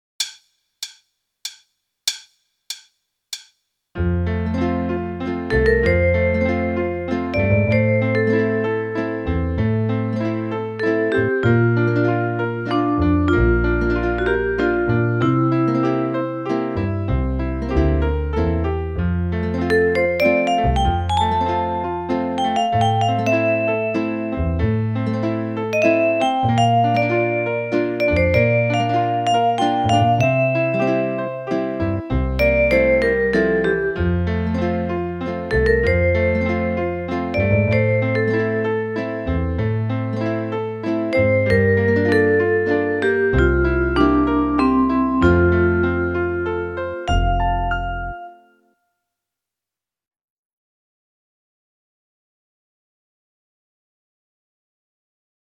Meespeel CD
40. Slow waltz